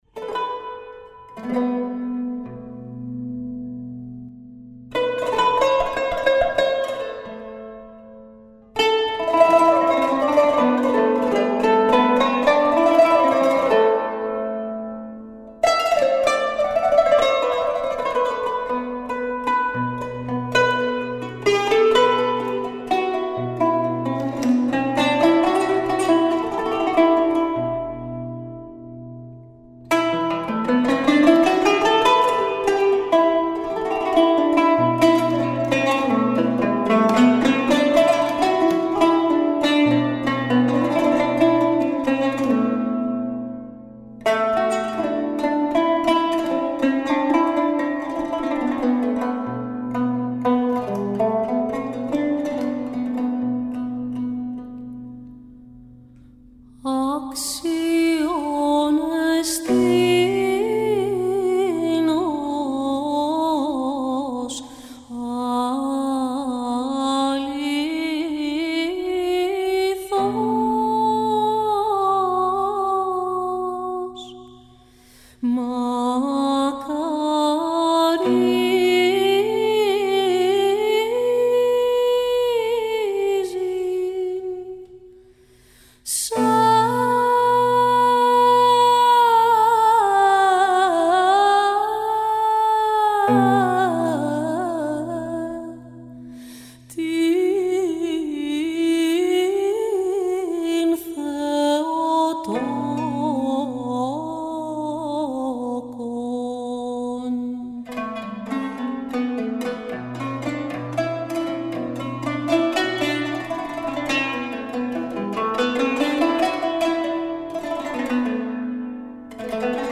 Στην εκπομπή ακούγονται τραγούδια που σκιαγραφούν το πέρασμα των εποχών από τον 10ο αιώνα με συνθέσεις της ποιήτριας και συνθέτριας Reine Blanche de Castille, παλαιότερα βυζαντινά θεοτοκία αλλά και συνθέσεις του ευρύτερου μεσογειακού ρεπερτορίου που τραγουδήθηκαν από γυναικείες φωνές.